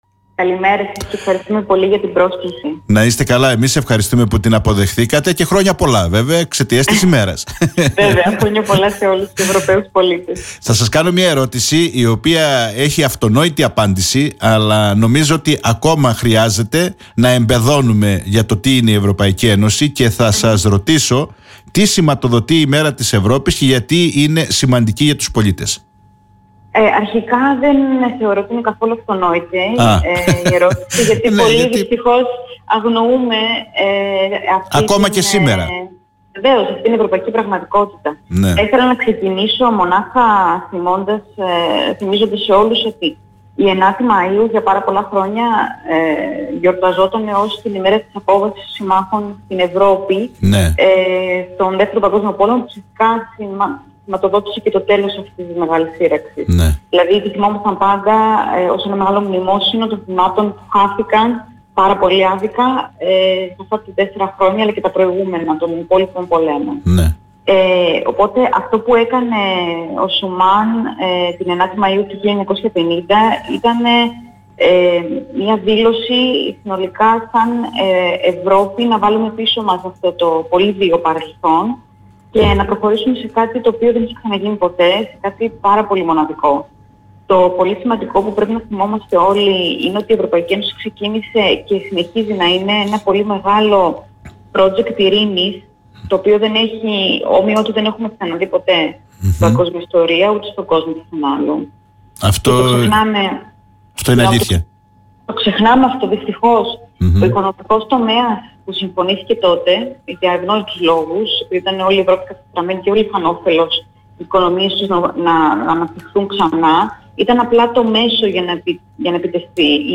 στην εκπομπή “Όμορφη Μέρα”